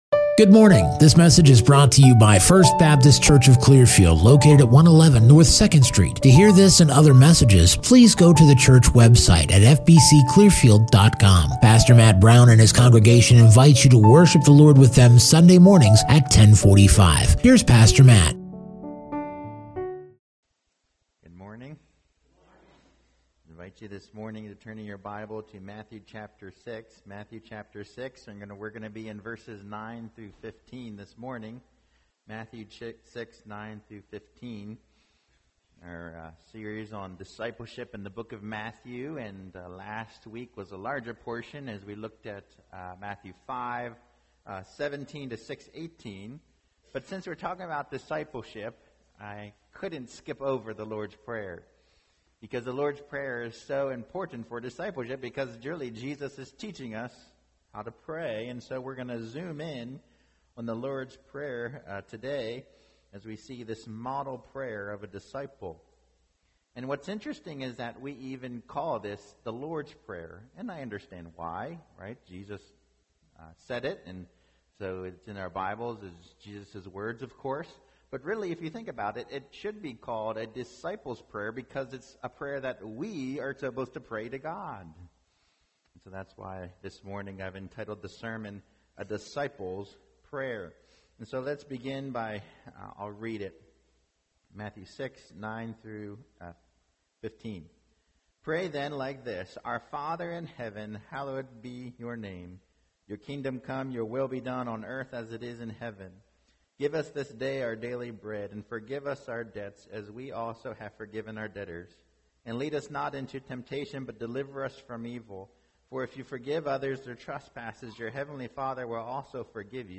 2023 A Disciple’s Prayer Preacher